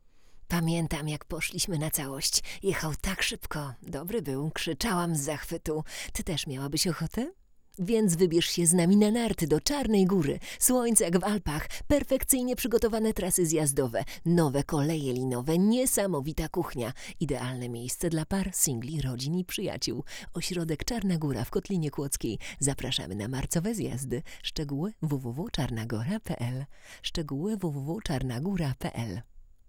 Nagranie wokal żeński
Szumy jak najbardziej w normie.
Helios brzmi równie dobrze w niskich jak i w wysokich pasmach.
Mięsiście, ciepło i blisko.